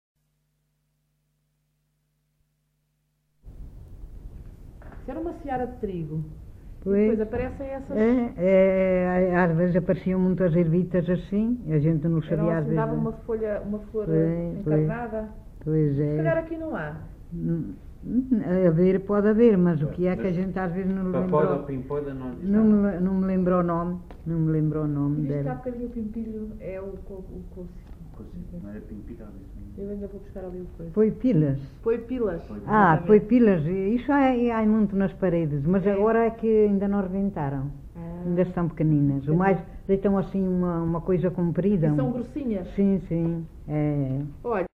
Assanhas (Figueiró da Serra), excerto 5
LocalidadeAssanhas (Celorico da Beira, Guarda)